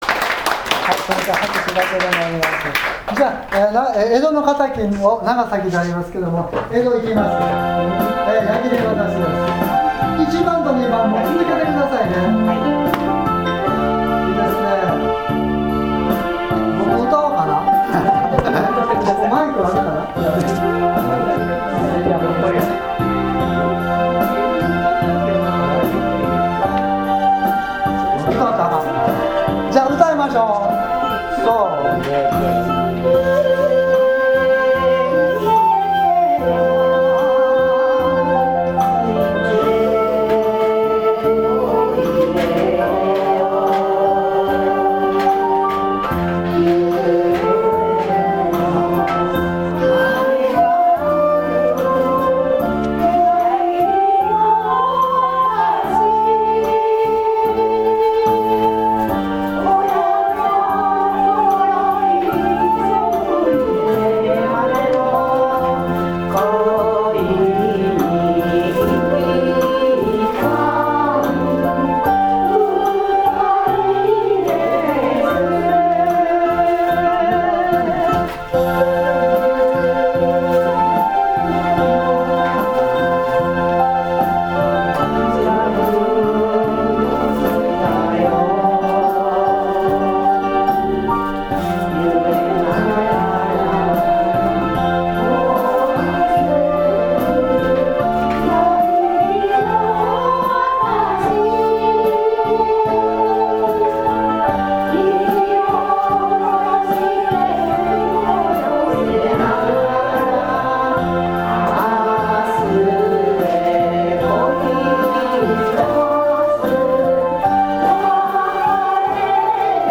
デイセンター：尺八演奏ボランティア（音源付き）
今回はいつも使う音色の上品な一尺六寸管（玉水作、七孔）をメインテナンスに出していますので地無し一尺八寸（節残し）を使うことにしました。
一尺八寸管で五孔ですし、マイクも使いませんので少々選曲に気をつけました。
伴奏は左手でコードを押さえると自動的に伴奏してくれるクラビノーバで多重録音し作ってみました。
思いの外、みなさん、気持ちが解き放たれた状態で歌っていました。
今回は、ていねいに尺八を演奏したときの「音楽の持つ力」を再確認できた有意義なミニコンサートでした。